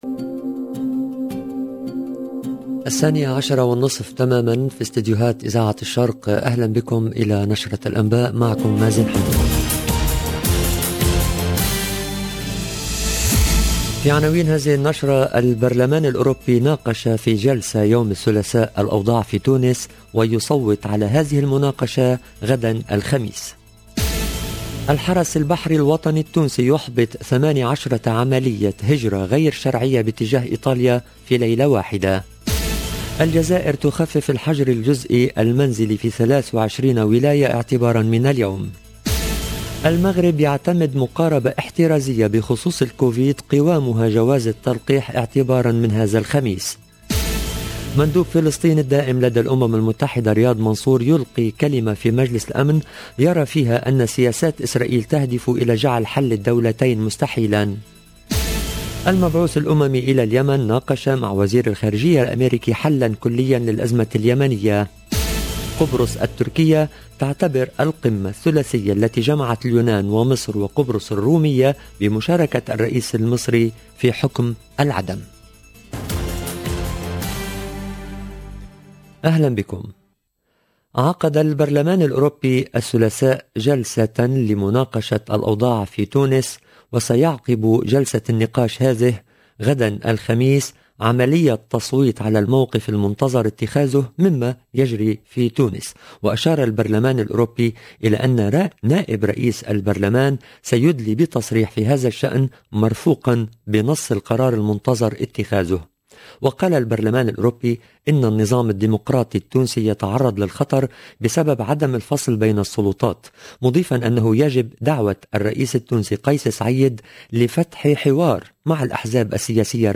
LE JOURNAL DE 12H30 EN LANGUE ARABE DU 20/10/2021